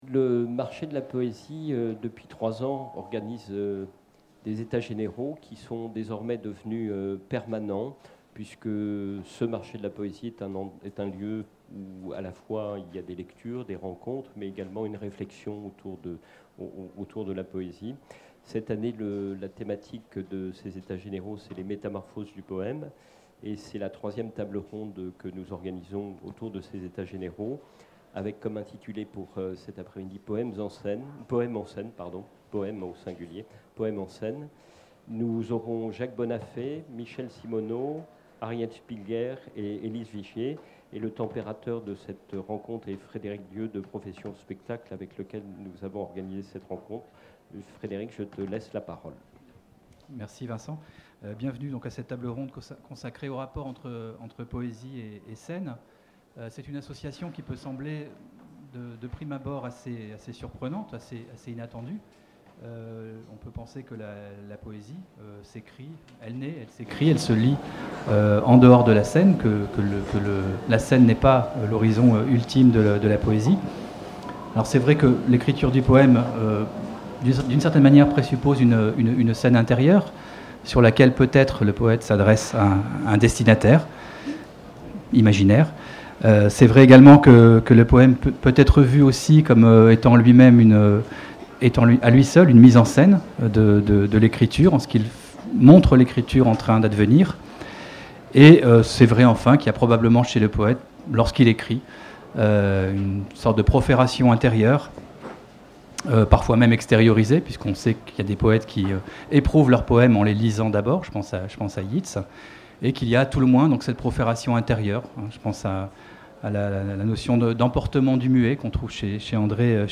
Scène Chapiteau du Marché
Table ronde Poème en scène